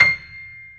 C6-PNO93L -L.wav